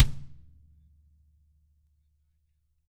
Index of /90_sSampleCDs/ILIO - Double Platinum Drums 2/Partition A/DW KICK D
DW FELT FD-R.wav